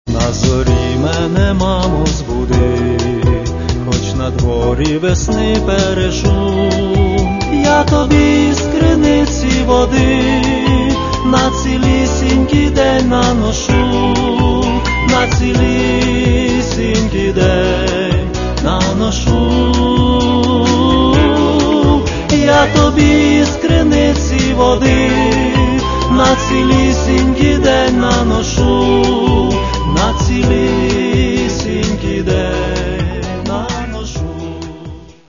Каталог -> MP3-CD -> Эстрада
Чтобы эти самые развлечения происходили весело и живенько.